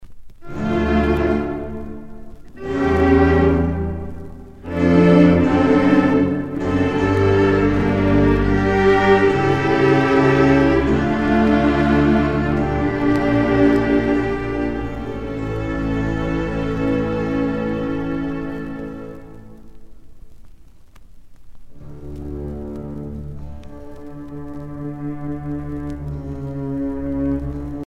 Concerto grosso fatto per la notte di natale